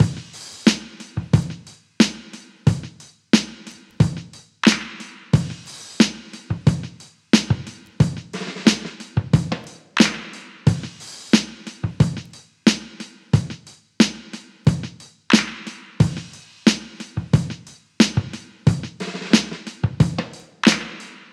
Free drum loop - kick tuned to the E note. Loudest frequency: 3089Hz
• 90 Bpm Drum Loop Sample E Key.wav
90-bpm-drum-loop-sample-e-key-YMR.wav